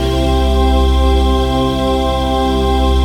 DM PAD2-87.wav